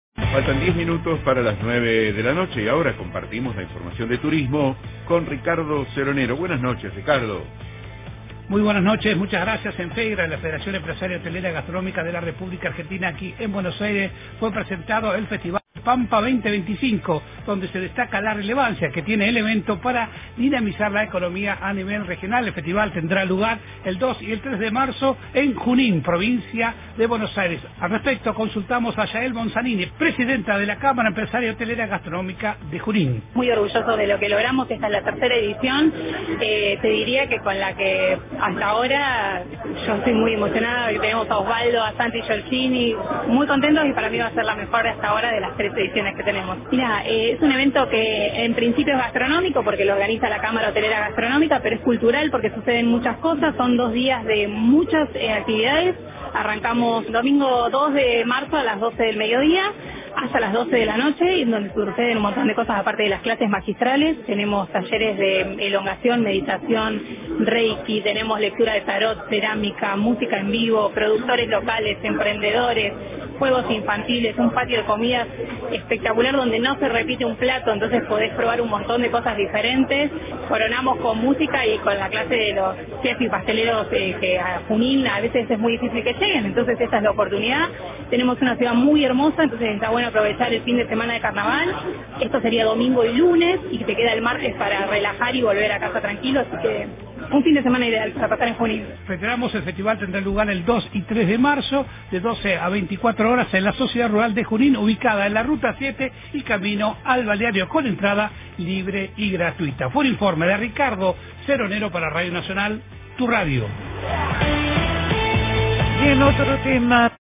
AM 870 Radio Nacional en vivo desde Buenos Aires a las 49 emisoras de todo el país.